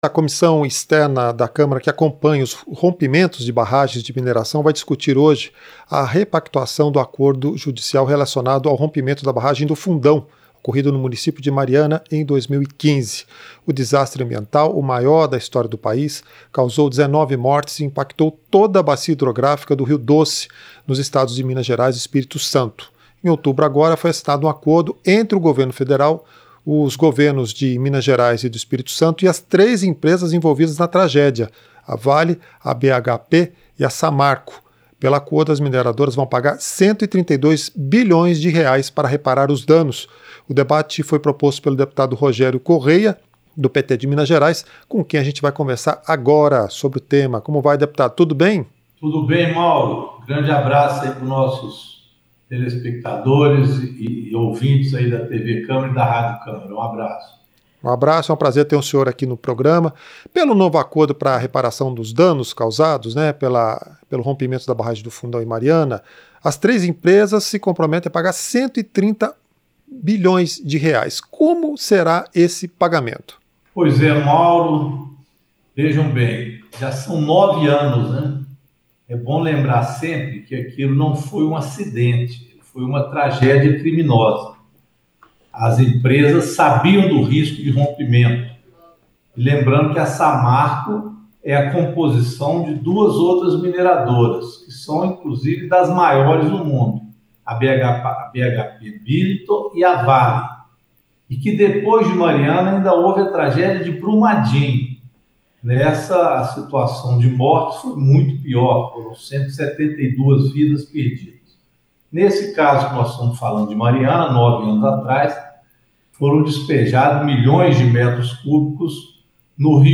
Entrevista - Dep. Rogério Correia (PT-MG)